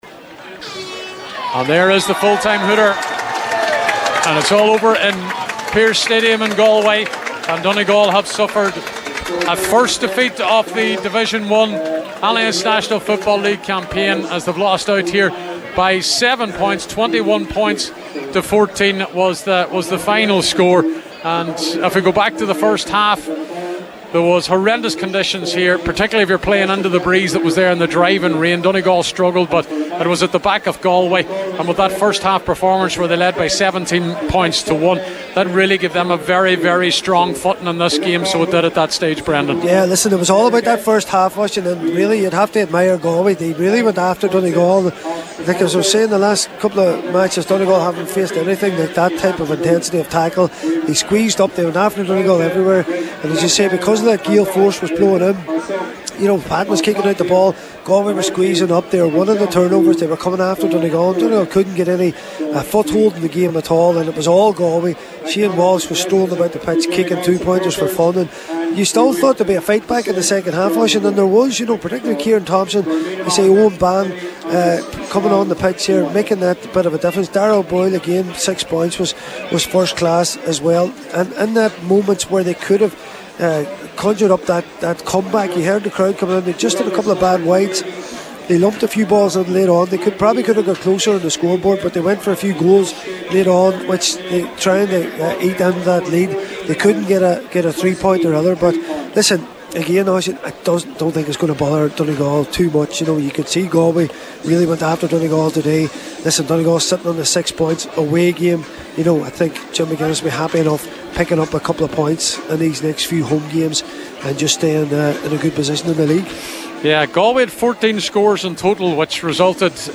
full time report from Pearse Stadium in Salthill…